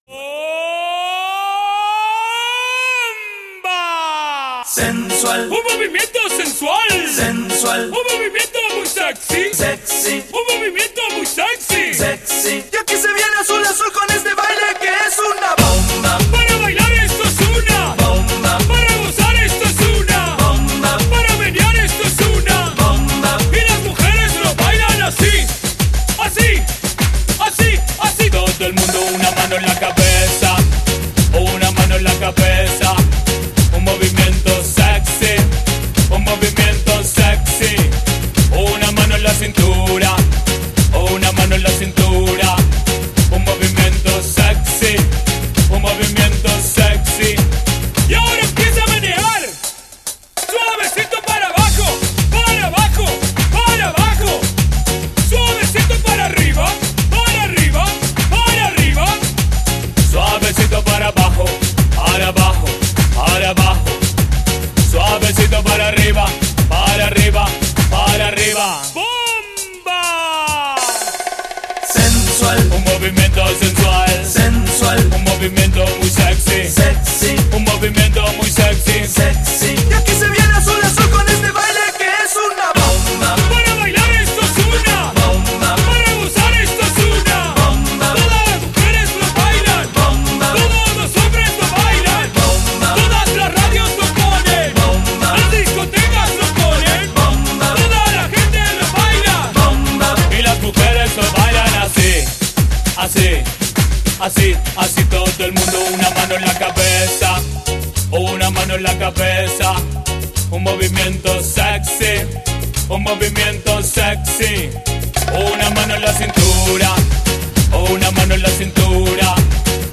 08 Samba